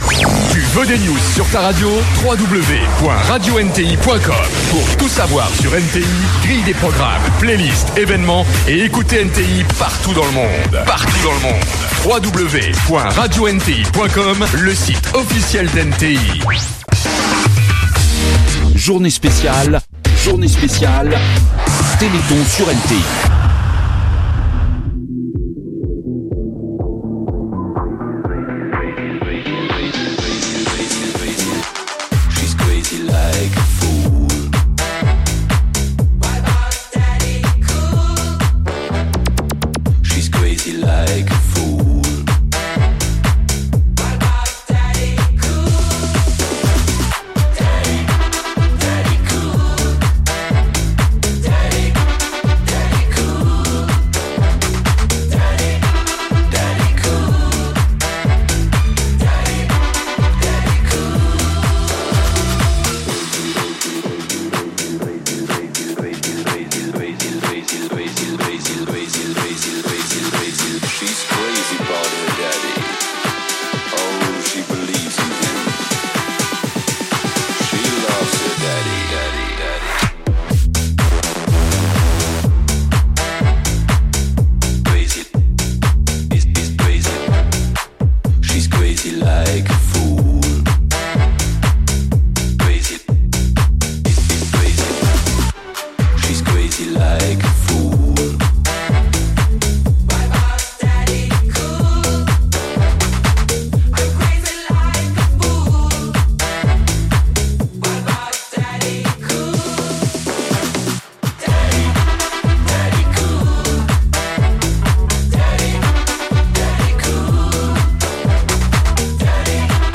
Mix
mix live